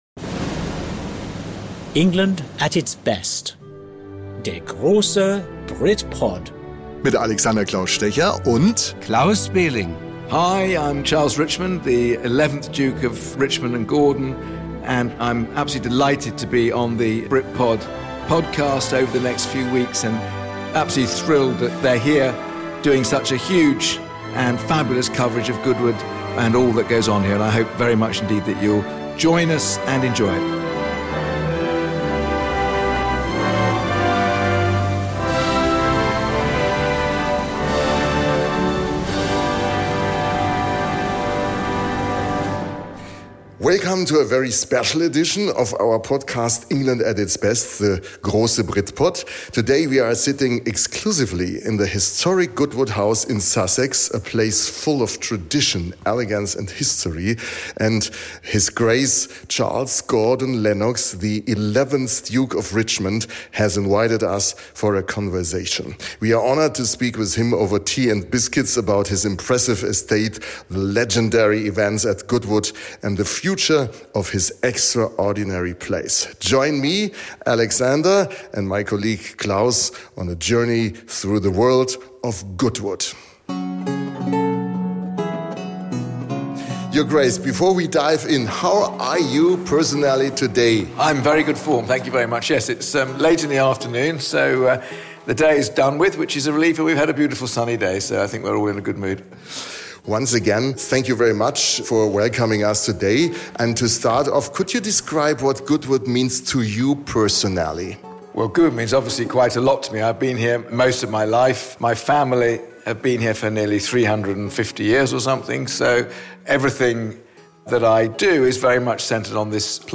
Exclusive Interview: Charles Gordon-Lennox, 11th Duke of Richmond on Tradition & Innovation (Original English, Part 1) ~ BRITPOD - England at its Best Podcast